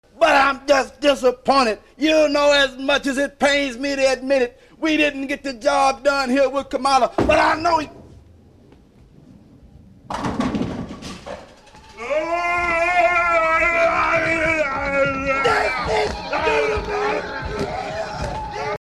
itsastrike.mp3